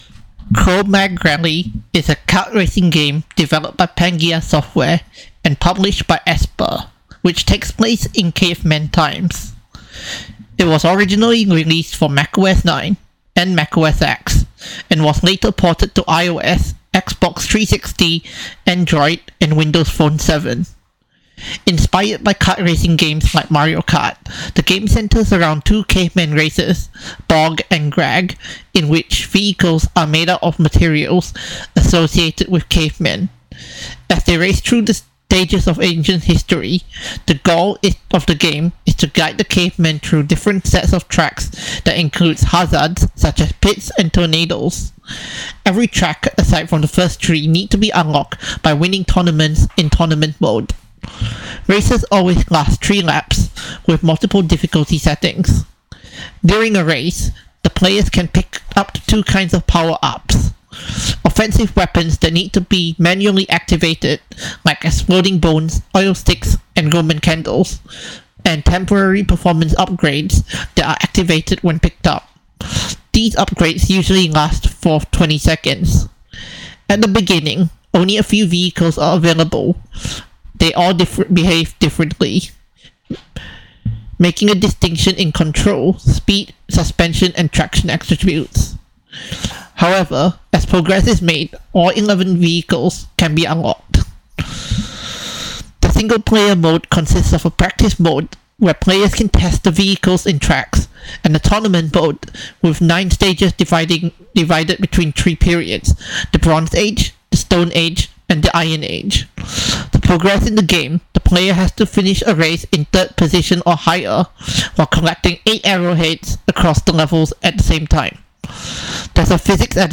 Note: My voice's reference may be a little dirty, but I used a cleaner and shorter (about 20 seconds) clip and the results were the same.